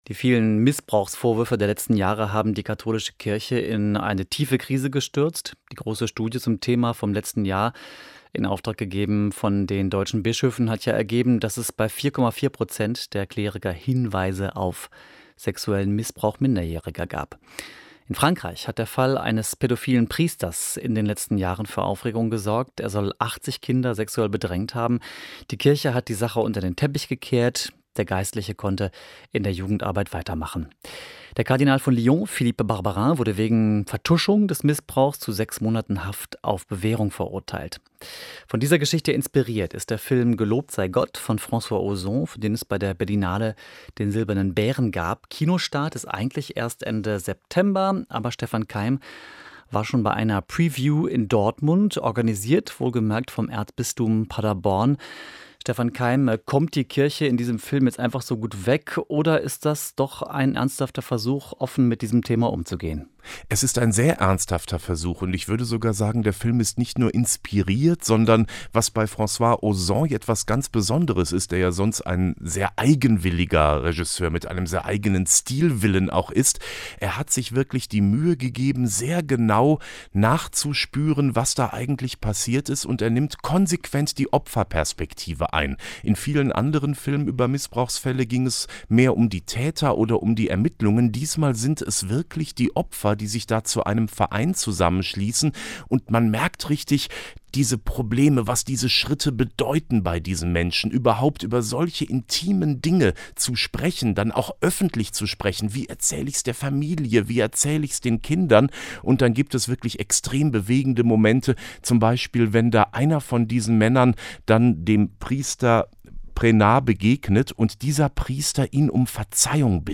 Rundfunkbeitrag